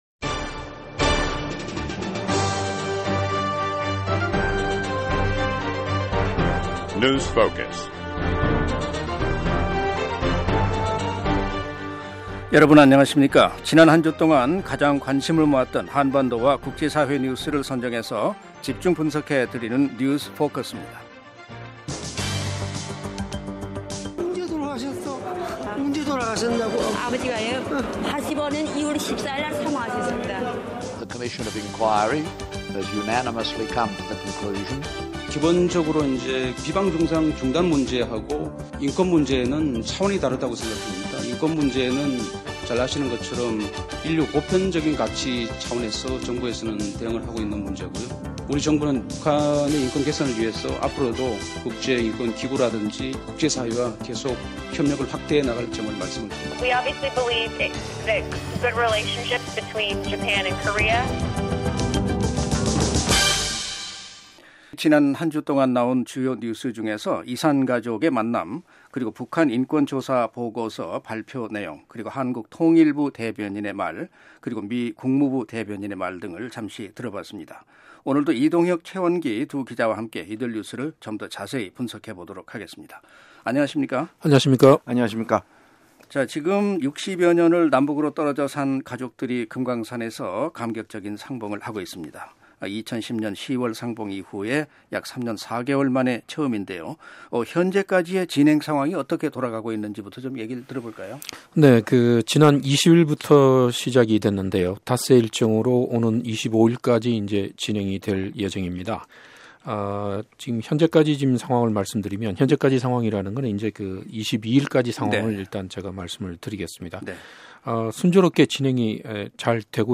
지난 한주 동안 가장 관심을 모았던 한반도와 국제사회의 뉴스를 선정해 집중 분석해드리는 뉴스 포커스입니다. 오늘도 남북 이산가족 상봉 소식, 유엔 북한 인권조사위원회 최종보고서 발표, 2014 소치 동계올림픽 소식 등 다양한 소식을 전해드립니다.